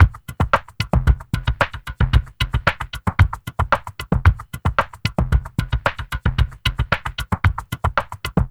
LQT LOFI M-L.wav